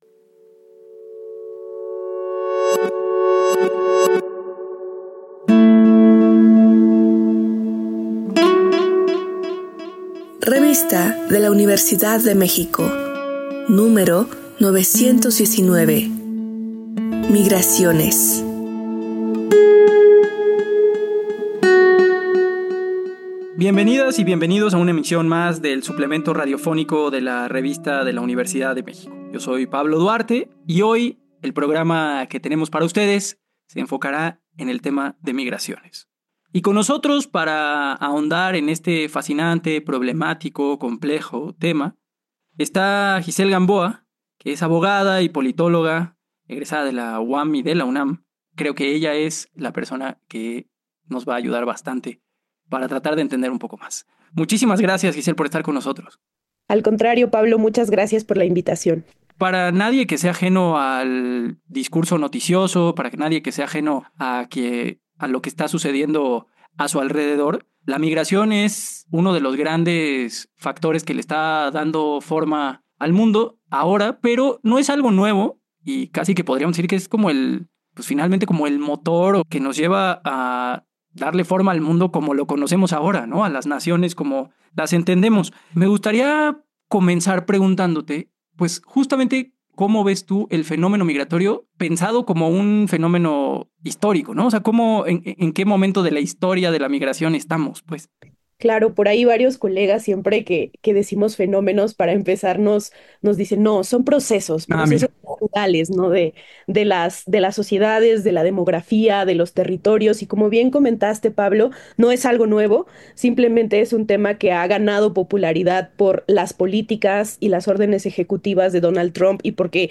Cargar audio Este programa es una coproducción de la Revista de la Universidad de México y Radio UNAM. Fue transmitido el jueves 17 de abril de 2025 por el 96.1 FM.